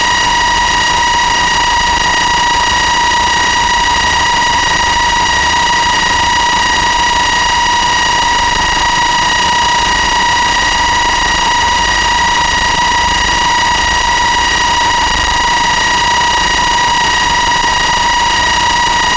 No Sharps plugged in -Serin working great, no data lost